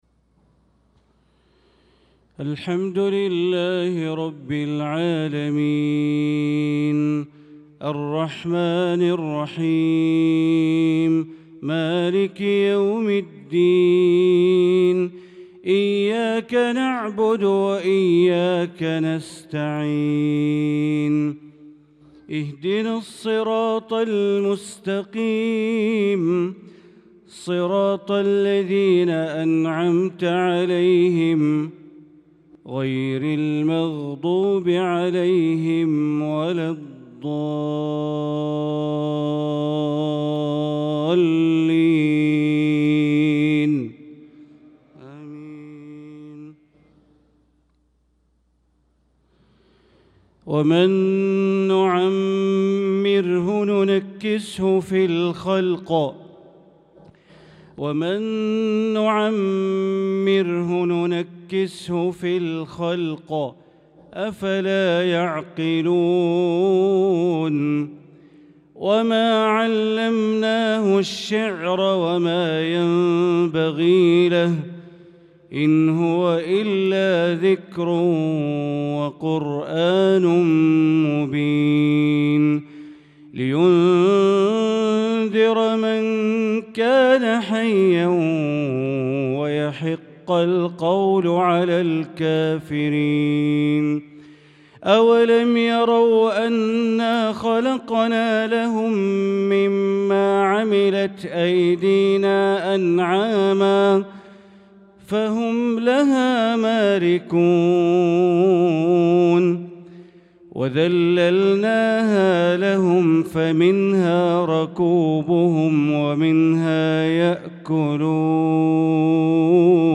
صلاة العشاء للقارئ بندر بليلة 2 ذو القعدة 1445 هـ
تِلَاوَات الْحَرَمَيْن .